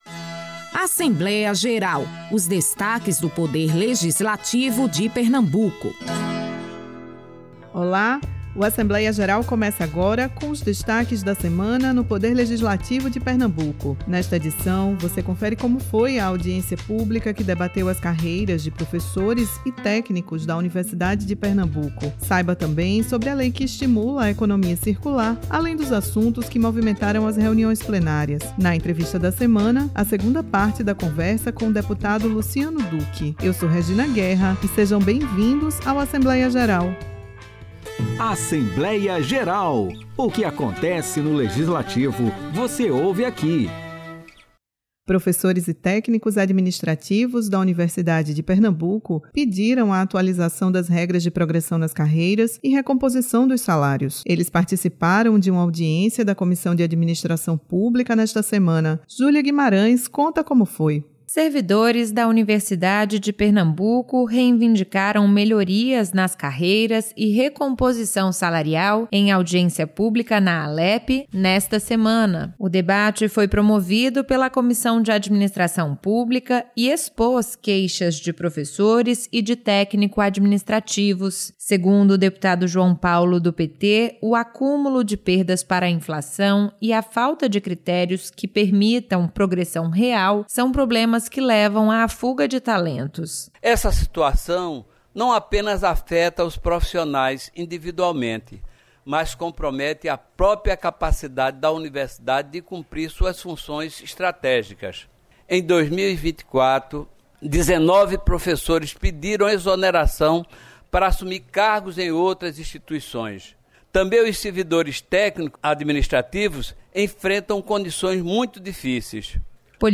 O Assembleia Geral desta semana traz a audiência pública que debateu as carreiras dos servidores da UPE, uma reportagem sobre a lei que estimula a economia circular e os assuntos que movimentaram as reuniões plenárias do período. O entrevistado da semana é o deputado Luciano Duque (Solidariedade).